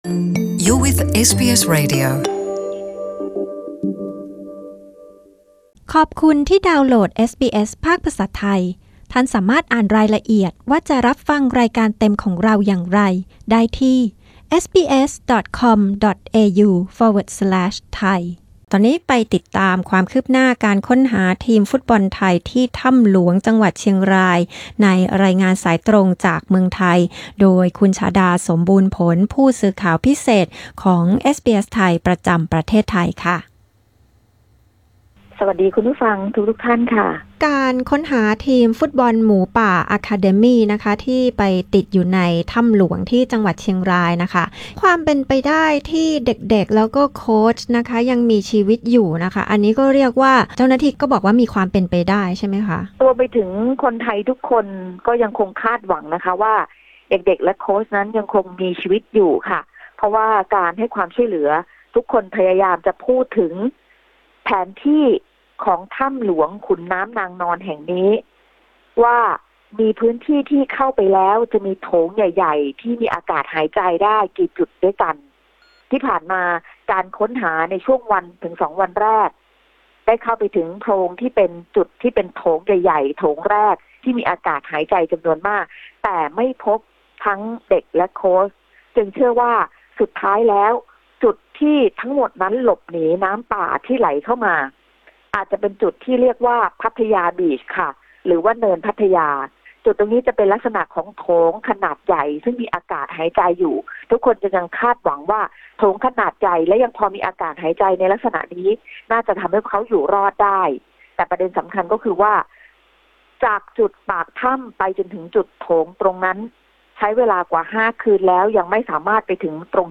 รายงานสายตรงจากเมืองไทยเมื่อคืนนี้ (28 มิ.ย.): ความคืบหน้าผู้สูญหายในถ้ำหลวง